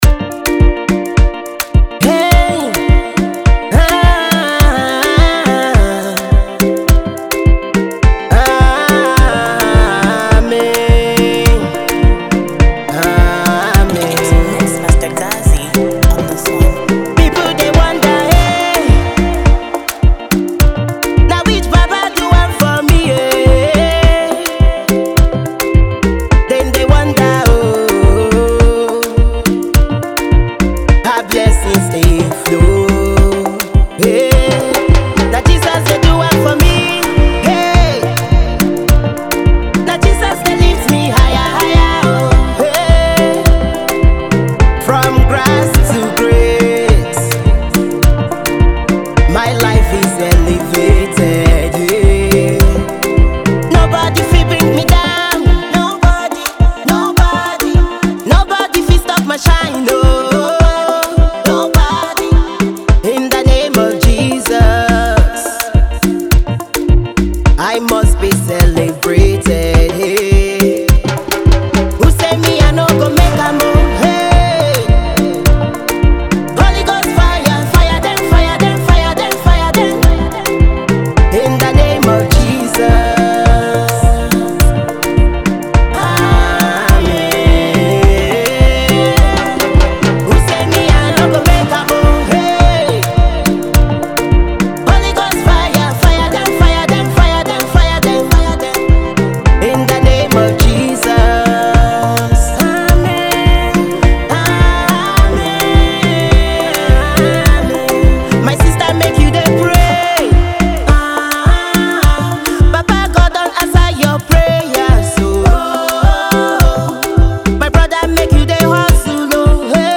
gospel singer
uptempo afrovibe single
prophetic prayer song
With its catchy chorus and beats